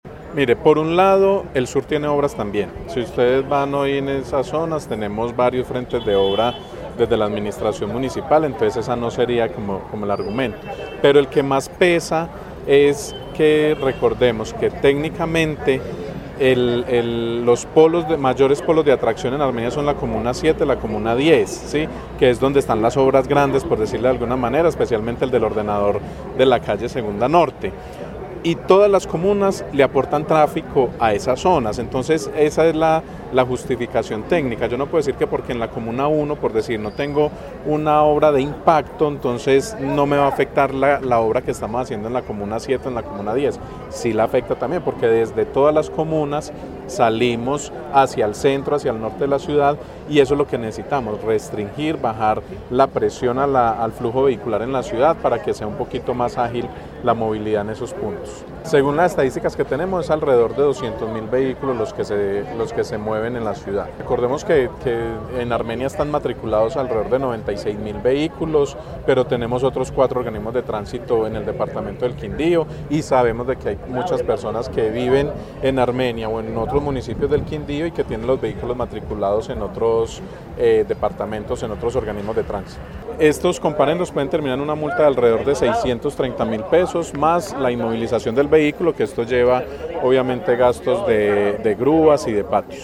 Secretario de Tránsito de Armenia